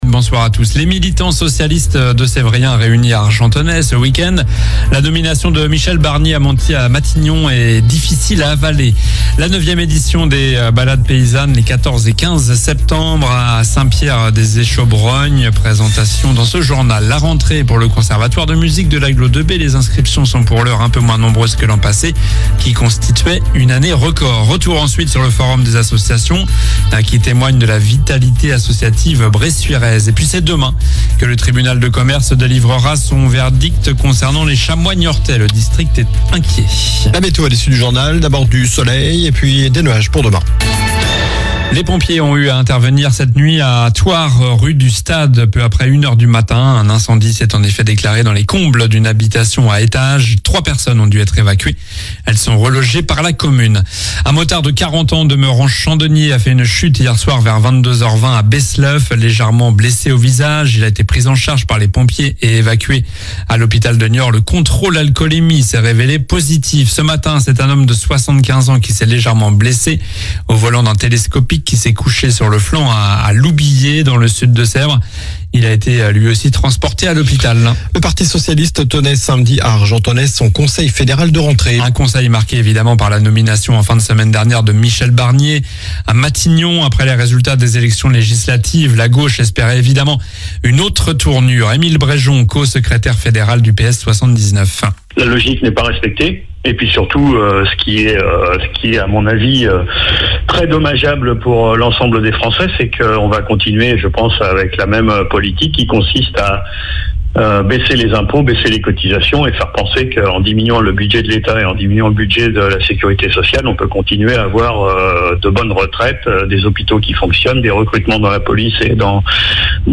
Journal du lundi 9 septembre (soir)